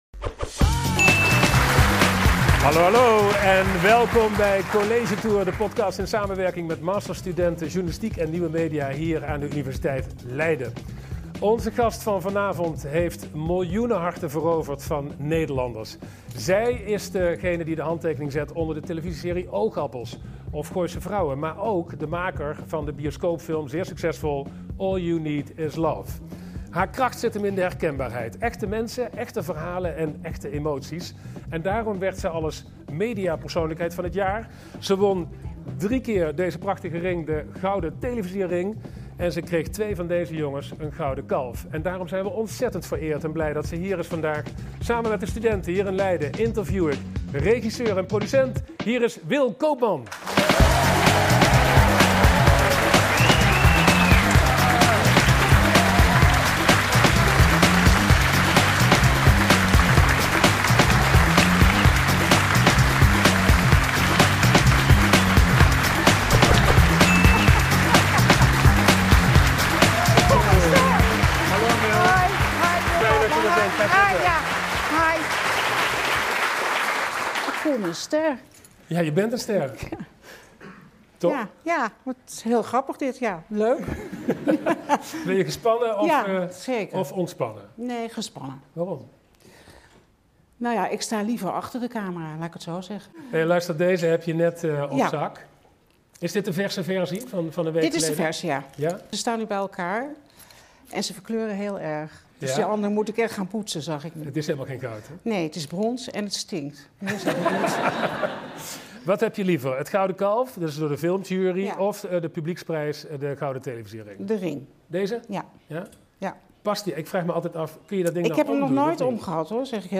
In de tweede aflevering van seizoen twee van College Tour de podcast! vragen Twan Huys en studenten in de zaal Will Koopman naar de kunst van het regisseren, haar werkverslaving, hoe om te gaan met onderschattingen van Nederlandse producties en nieuwe series in de maak. Dit interview met Will Koopman werd opgenomen collegezaal Lipsius 011 aan de Universiteit Leiden op 13 oktober 2025.